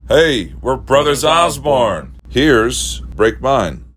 LINER Brothers Osborne (Break Mine) 1